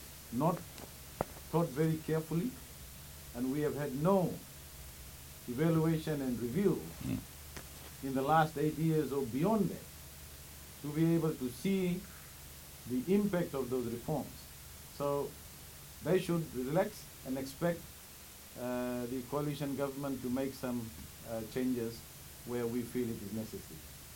Finance Minister, Professor Biman Prasad says the former Education Minister should realize that many of the reforms that happened under the previous government was haphazard among other things.